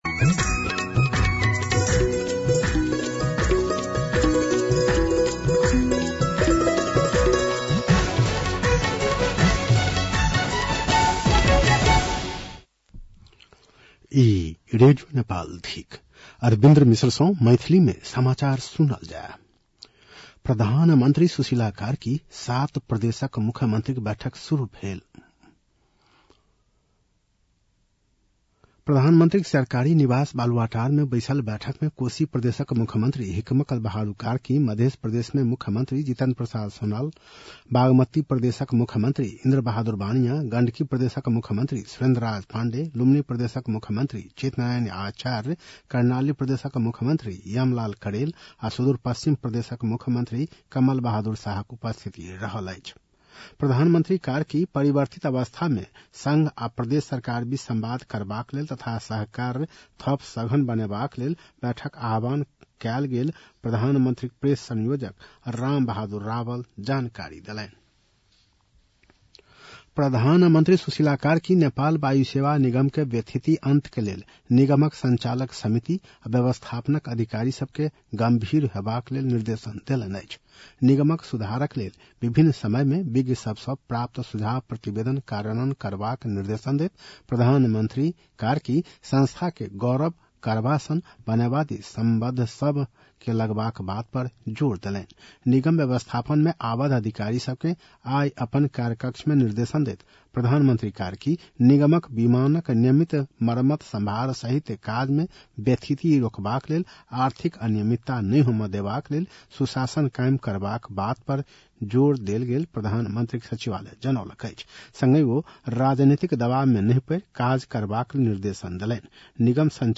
मैथिली भाषामा समाचार : १६ कार्तिक , २०८२